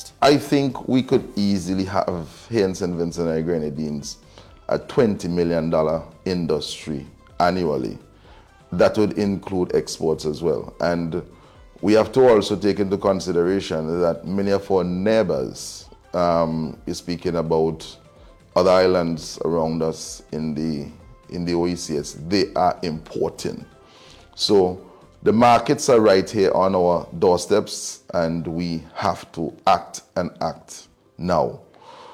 The Minster who was speaking on the API’s Marnin SVG last week explained that with the expansion in the hotel industry he did not want to be a country that would be importing flowers.